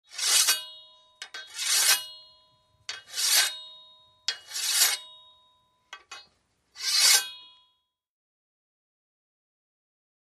Sword Shing: Metal Unsheathed.